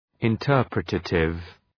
Shkrimi fonetik{ın’tɜ:rprə,teıtıv}